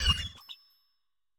Cri de Frigodo dans Pokémon Écarlate et Violet.